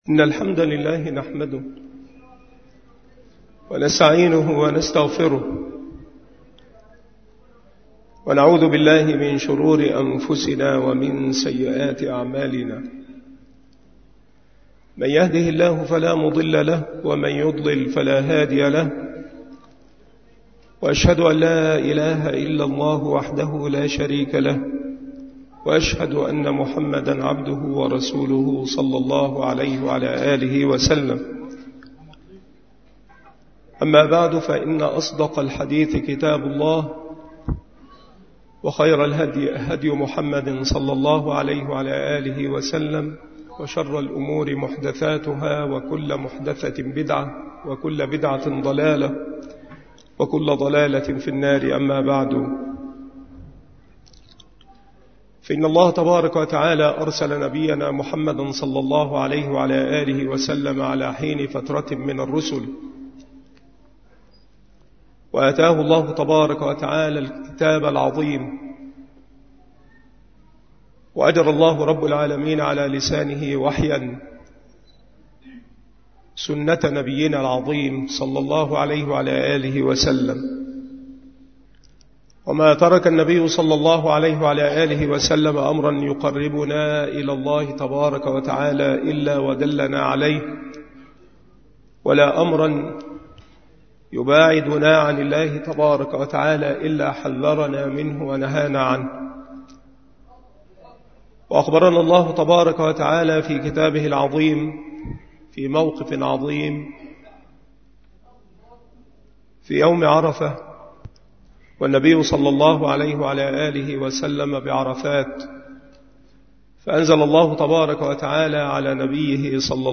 مكان إلقاء هذه المحاضرة بمسجد أولاد غانم - منوف - محافظة المنوفية - مصر